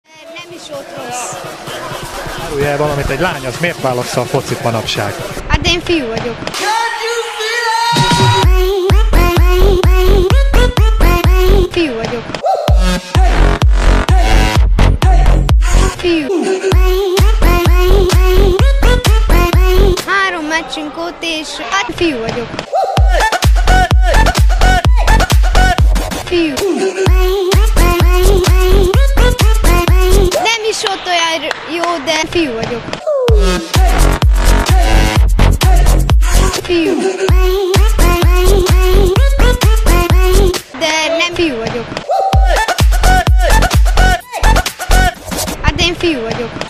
Kategória: Vicces
Minőség: 320 kbps 44.1 kHz Stereo